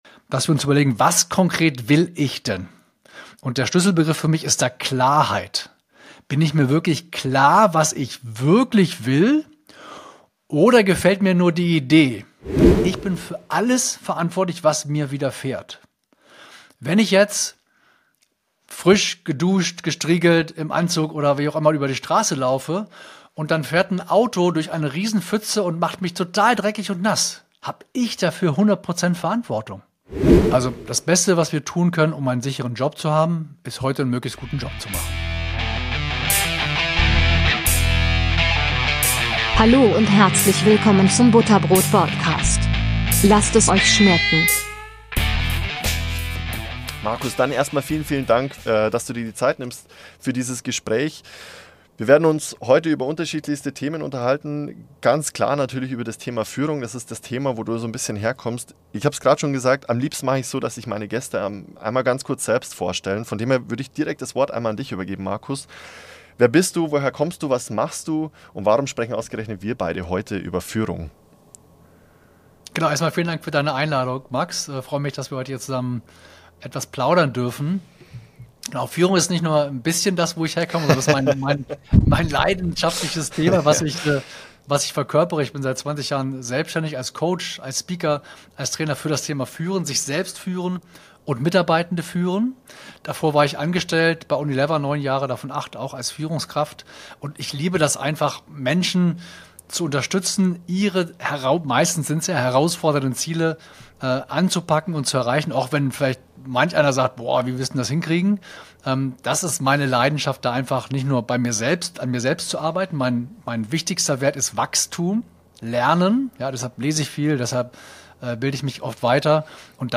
In diesem Gespräch geht es um die Themen Führung, Selbstführung und Verantwortung.